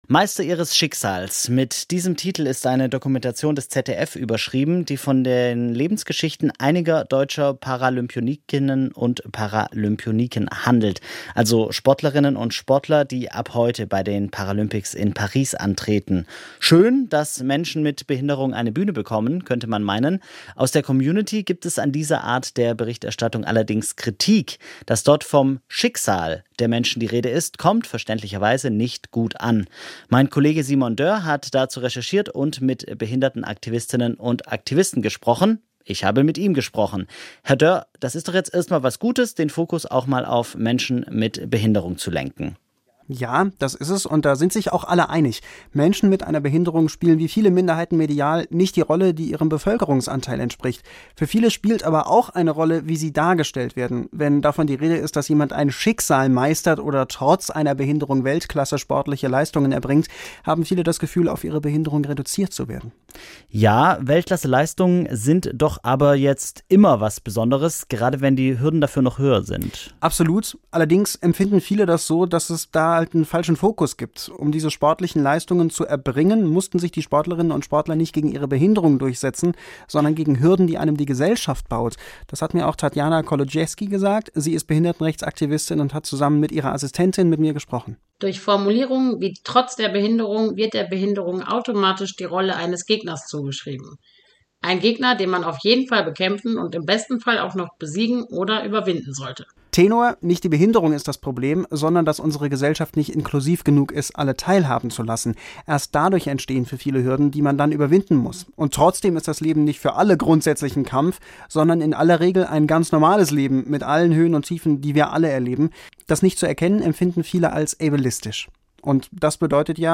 start-der-paralympics-warum-es-in-der-berichterstattung-auf-die-richtige-wortwahl-ankommt.m.mp3